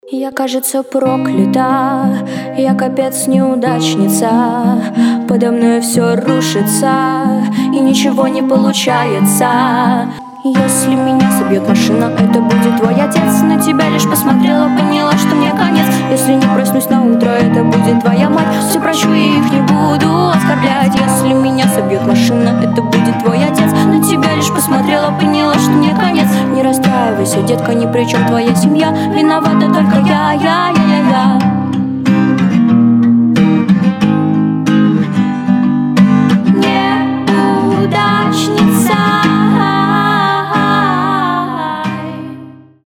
• Качество: 320, Stereo
гитара
грустные
ироничные